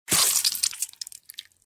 bt_split_blood.mp3